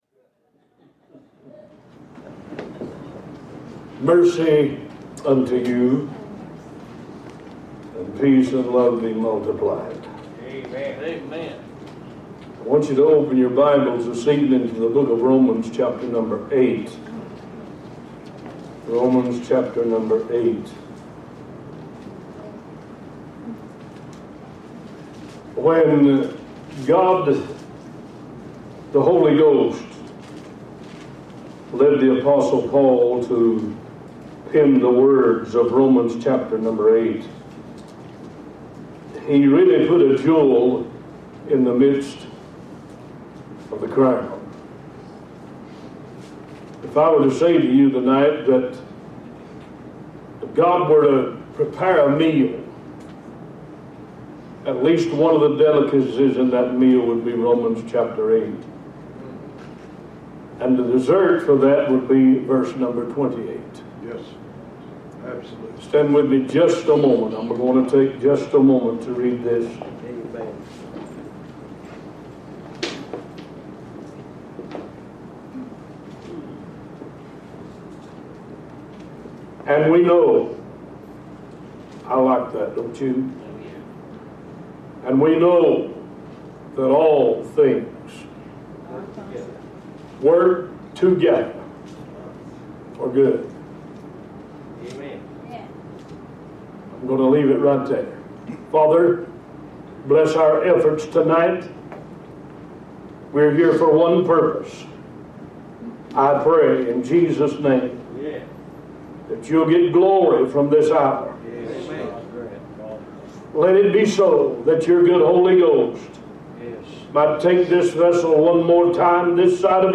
Talk Show Episode, Audio Podcast, One Voice and Romans 8:28 We Know on , show guests , about Romans 8 28 We Know, categorized as Health & Lifestyle,History,Love & Relationships,Philosophy,Psychology,Christianity,Inspirational,Motivational,Society and Culture